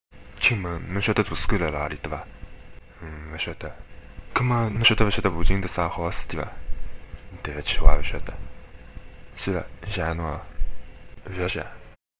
Can you work out what language this short conversation is in, and what it means?